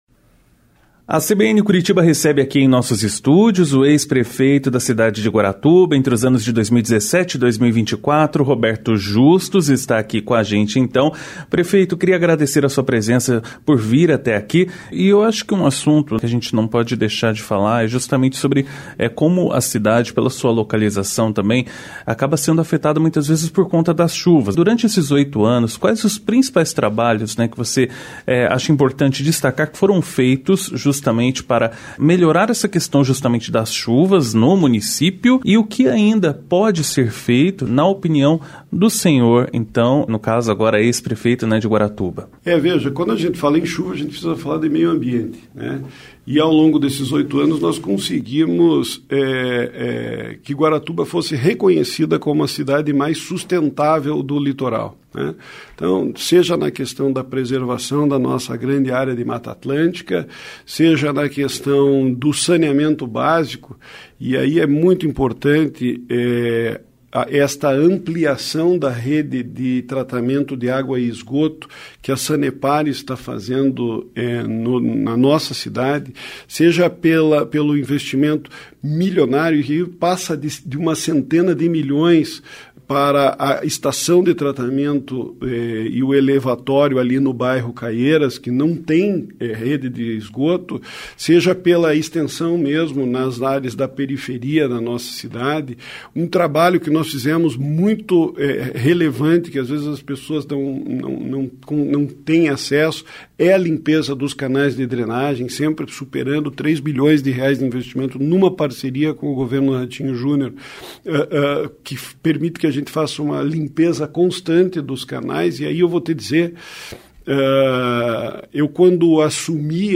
ENTREVISTA-PREFEITO-GUARATUBA-REDUZIDA.mp3